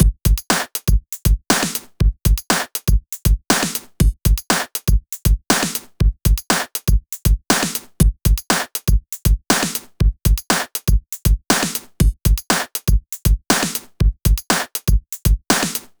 リズムマシン　聞き比べ
自宅録音で使っているリズムマシンの音です
ちなみにエフェクトは一切かけていません。